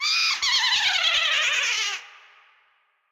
Sound effect from New Super Mario Bros.
Boo_Laugh_3.oga.mp3